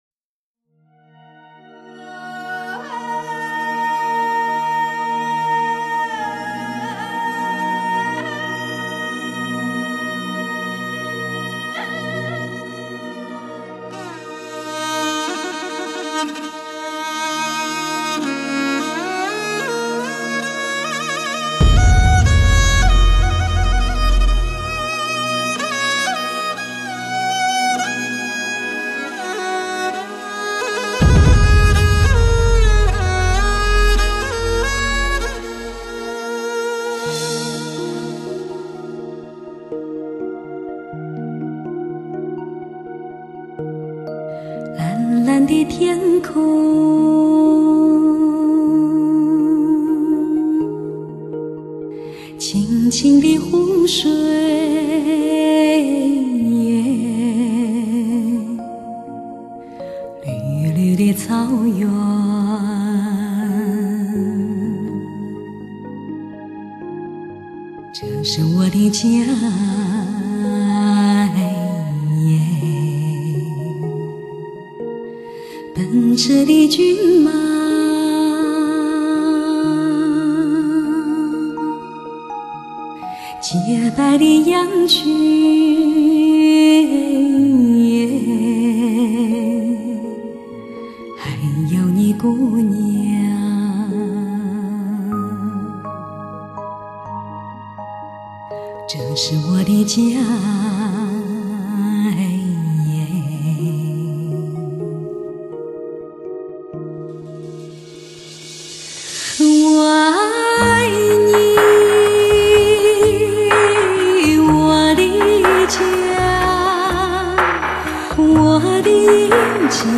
绝美的天籁音色，纯粹的发烧选曲，独特的韵味诠释；
马背上的歌声自由豪迈、动人心弦，是向苍天歌唱的民族宣言。
精选广为流传的草原经典民歌，